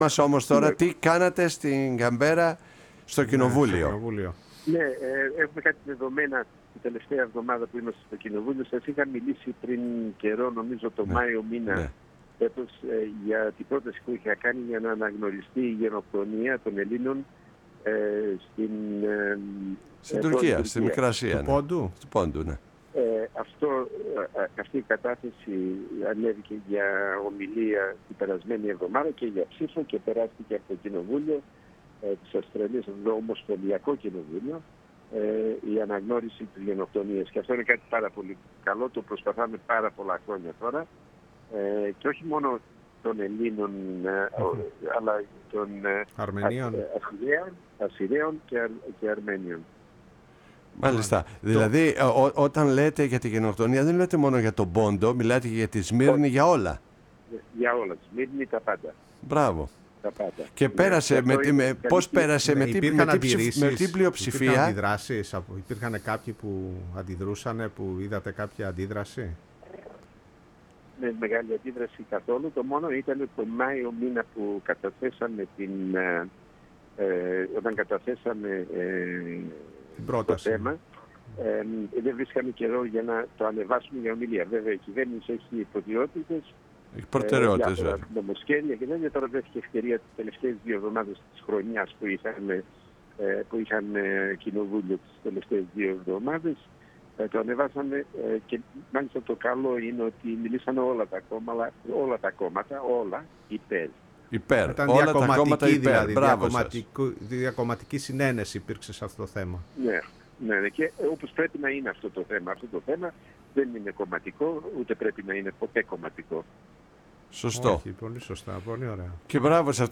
Στις προσπάθειες της ομογένειας της Αυστραλίας αλλά και του ιδίου να ψηφίσει η Βουλή Αντιπροσώπων της Αυστραλίας υπέρ της αναγνώρισης της γενοκτονίας των Ελλήνων της Μικράς Ασίας, της Θράκης και του Πόντου, των Αρμενίων και των Ασσύριων αναφέρθηκε ο ομοσπονδιακός βουλευτής Στάθης Γεωργανάς.
Μιλώντας στην εκπομπή «Η Παγκόσμια Φωνή μας» στη Φωνή της Ελλάδος